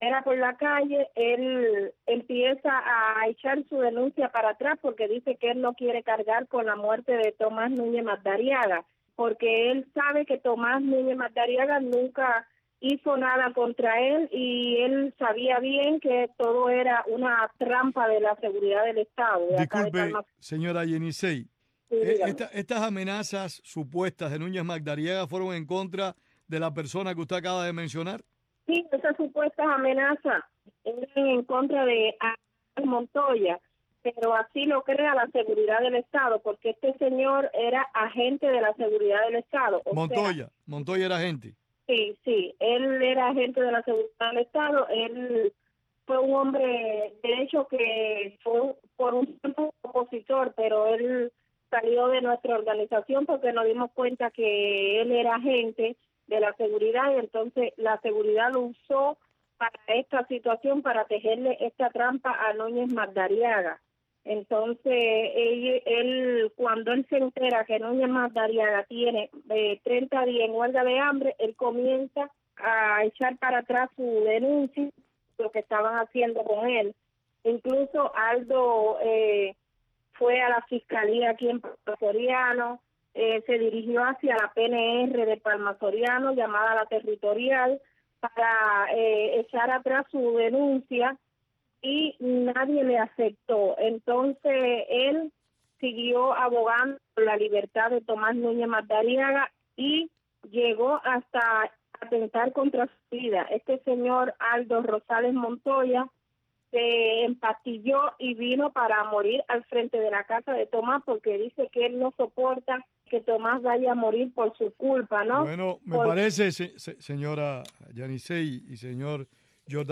Puntos de Vista, es un programa de Radio Tv Martí, en el que se analizan informaciones noticiosas y temas de interés público con invitados que poseen amplios conocimientos sobre los asuntos a tratar y valoraciones propias sobre los acontecimientos.